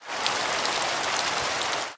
Minecraft Version Minecraft Version 25w18a Latest Release | Latest Snapshot 25w18a / assets / minecraft / sounds / ambient / weather / rain2.ogg Compare With Compare With Latest Release | Latest Snapshot
rain2.ogg